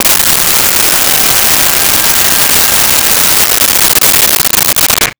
Blender On Chop
Blender on Chop.wav